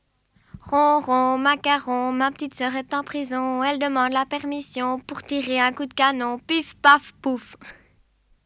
Type : chanson de Saint-Nicolas Aire culturelle d'origine : Basse-Meuse Interprète(s